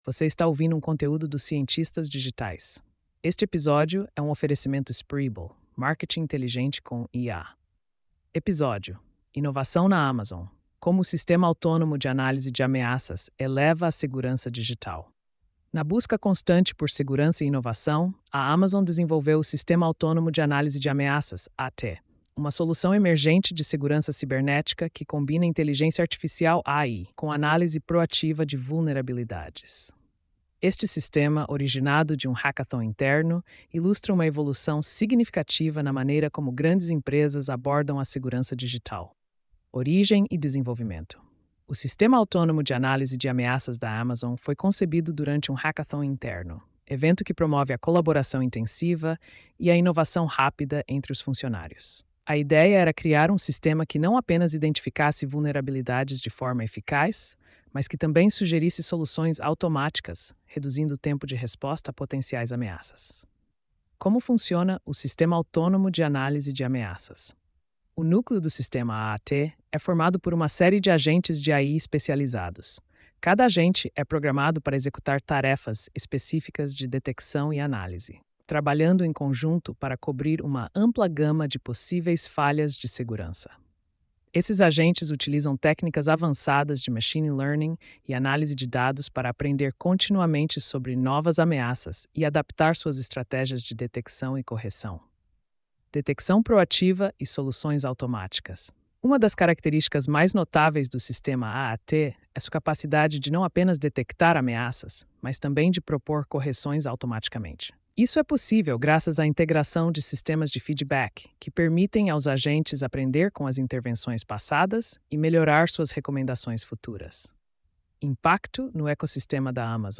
post-4551-tts.mp3